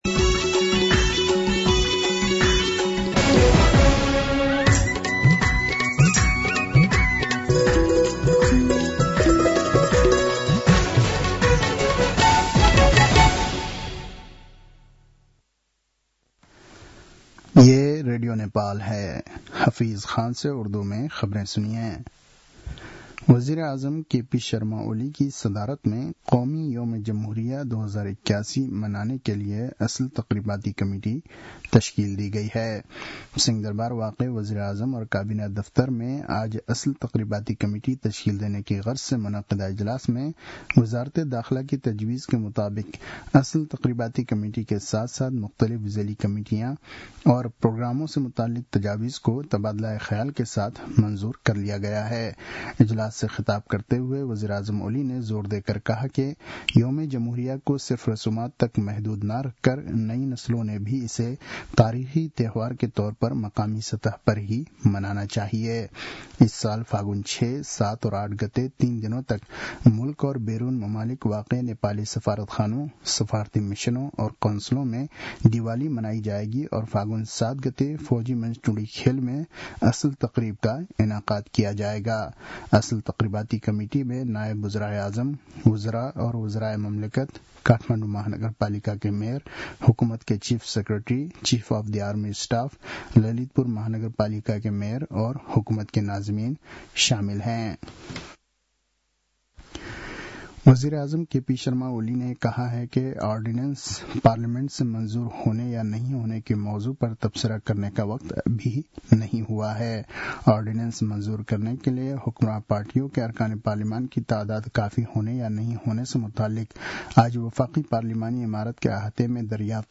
उर्दु भाषामा समाचार : २८ माघ , २०८१